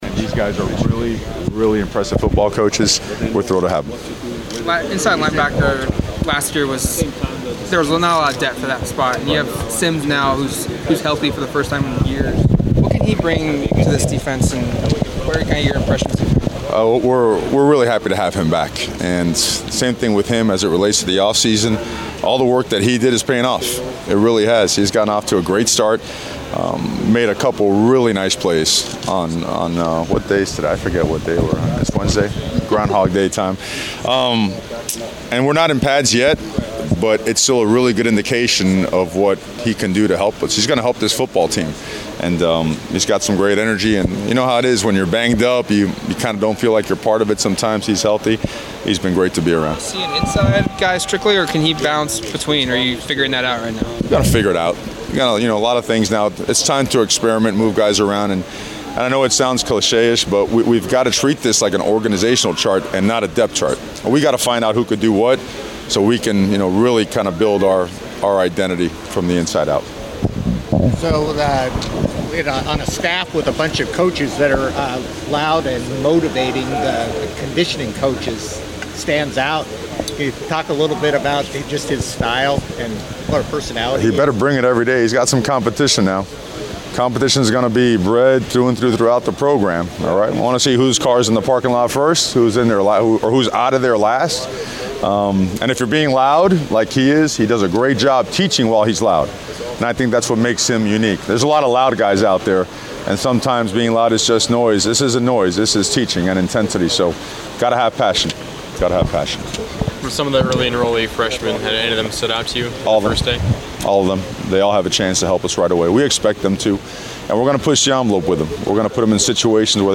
Mario Cristobal Media Session 3-9-18